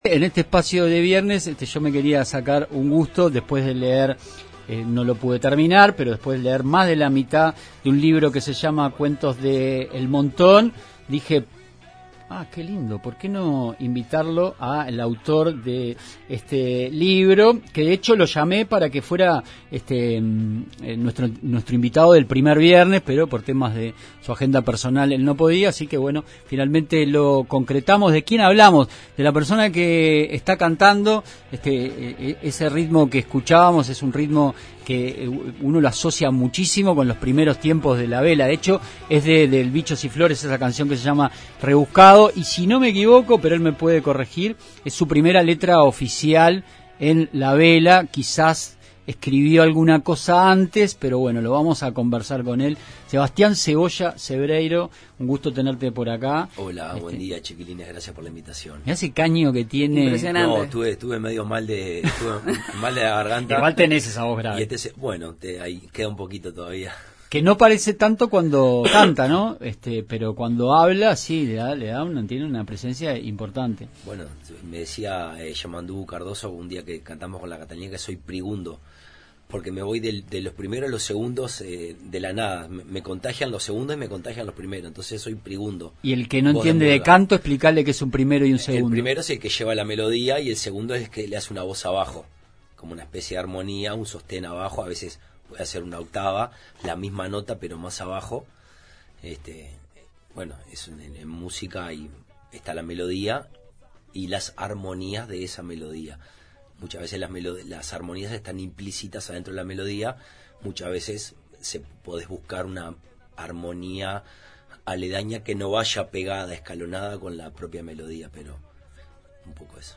Entrevista a Sebastián Cebreiro, escritor e integrante de La Vela Puerca